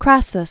(krăsəs), Marcus Licinius 115?-53 BC.